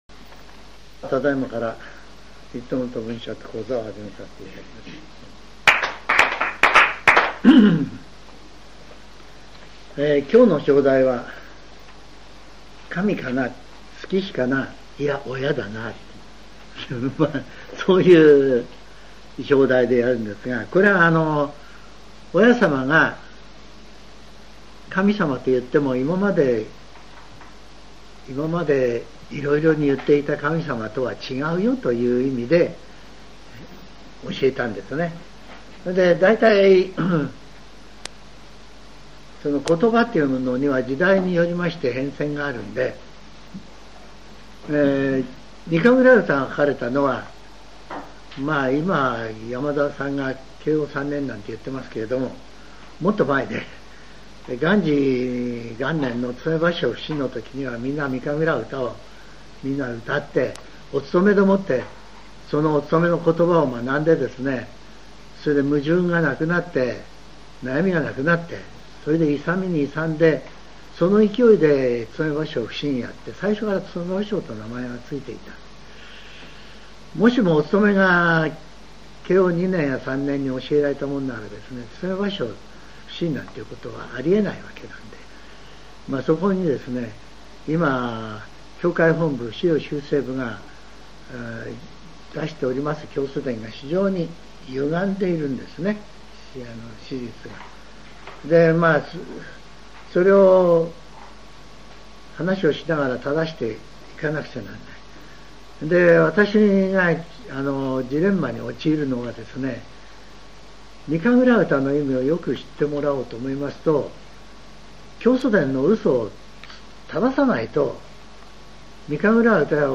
全70曲中56曲目 ジャンル: Speech